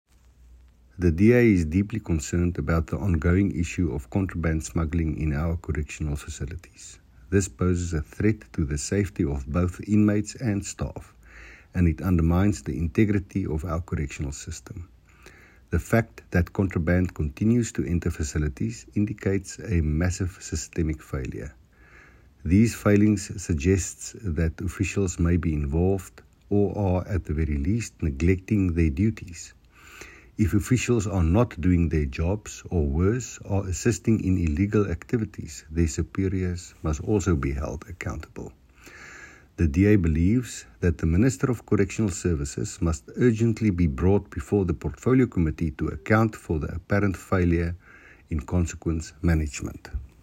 soundbite by Janho Engelbrecht MP.